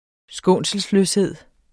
Udtale [ ˈsgɔˀnsəlsløsˌheðˀ ]